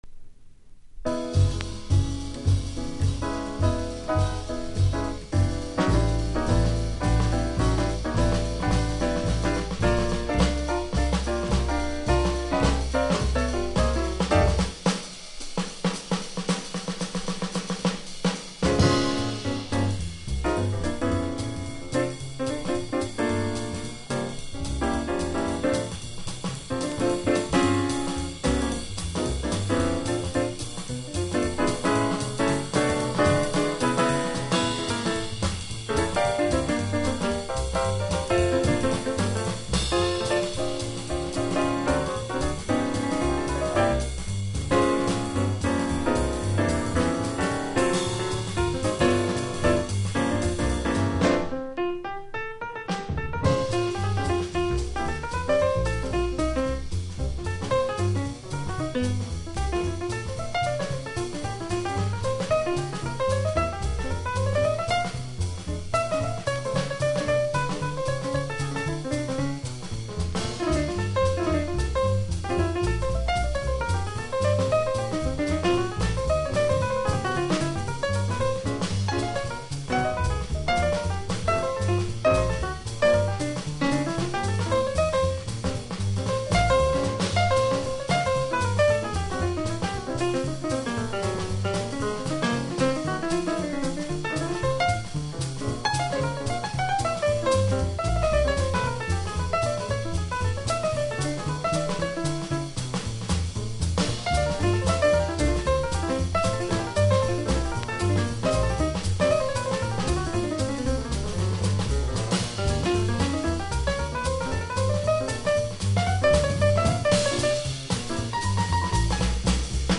（プレス・小傷によりチリ、プチ音ある曲あり）※曲名をクリックすると試聴で…